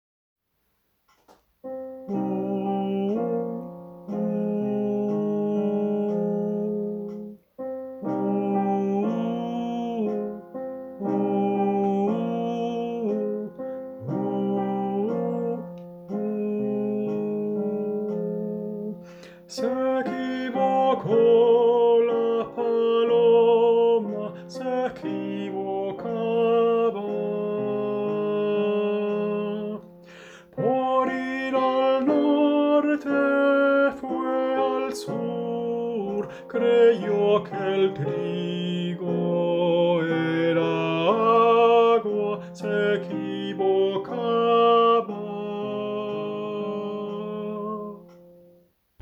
la paloma-Tenor 1.m4a